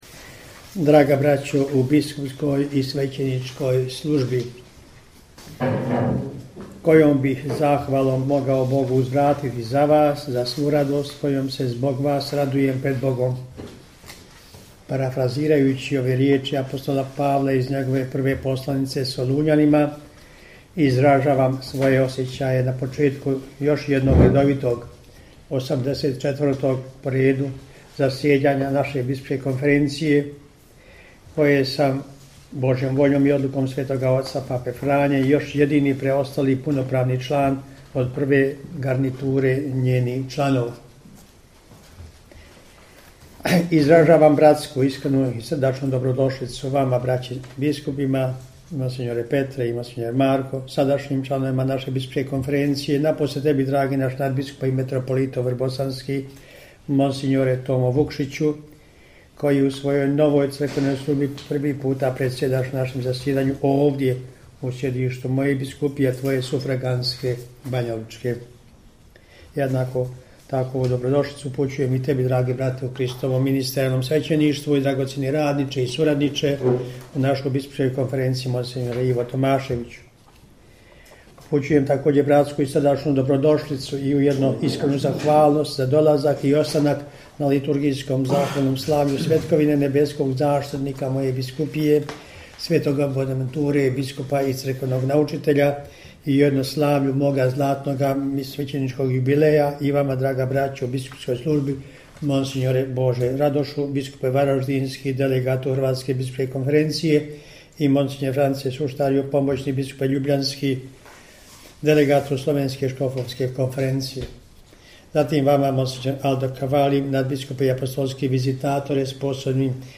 AUDIO: POZDRAVNA RIJEČ DOMAĆINA BISKUPA KOMARICE NA POČETKU 84. ZASJEDANJA BISKUPSKE KONFERENCIJE BOSNE I HERCEGOVINE
Na početku 84. redovitog zasjedanja Biskupske konferencije Bosne i Hercegovine, koje je započelo s radom u srijedu, 13. srpnja 2022. u prostorijama Biskupskog ordinarijata u Banjoj Luci pod predsjedanjem nadbiskupa metropolita vrhbosanskog i apostolskog upravitelja Vojnog ordinarijata u BiH mons. Tome Vukšića, predsjednika BK BiH, biskup banjolučki mons. Franjo Komarica uputio je pozdravnu riječ koju prenosimo u cijelosti: